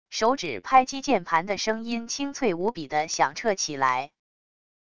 手指拍击键盘的声音清脆无比的响彻起来wav音频